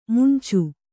♪ muncu